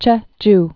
(chĕj)